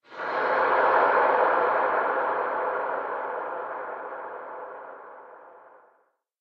Minecraft Version Minecraft Version latest Latest Release | Latest Snapshot latest / assets / minecraft / sounds / ambient / cave / cave4.ogg Compare With Compare With Latest Release | Latest Snapshot
cave4.ogg